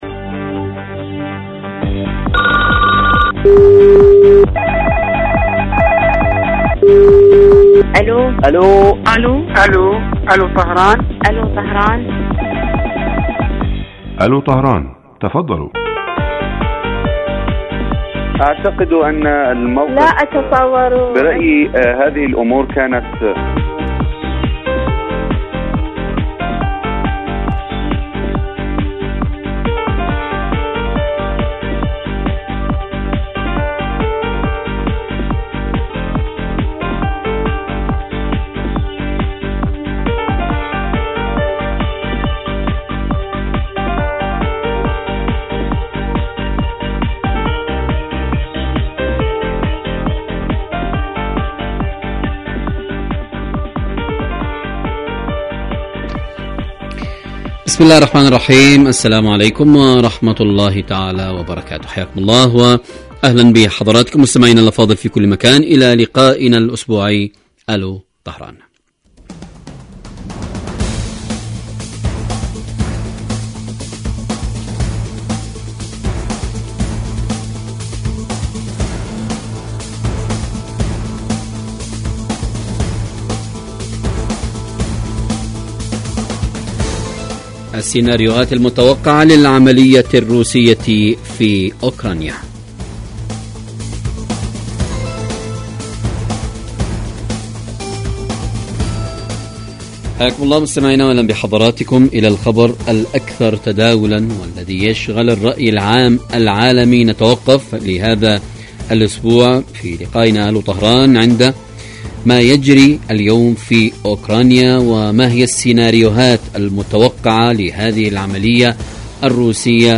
الكاتب والمحلل السياسي من إيران.